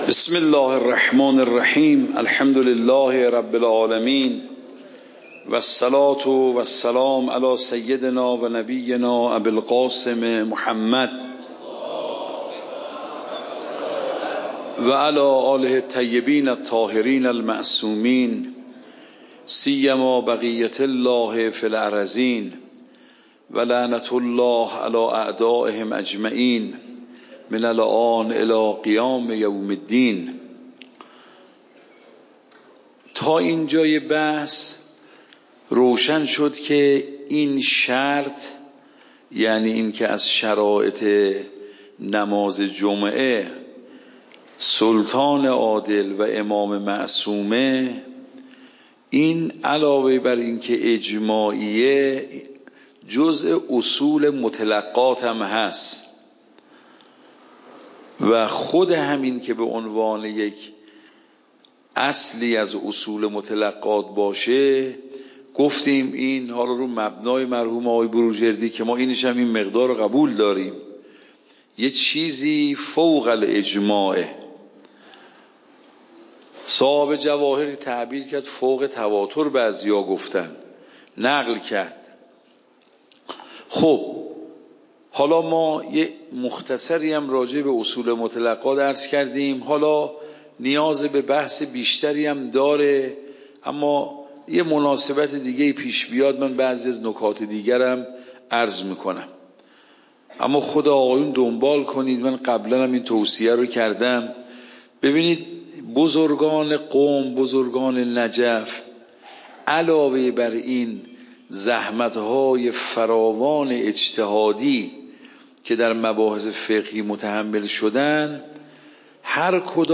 خارج فقه
صوت درس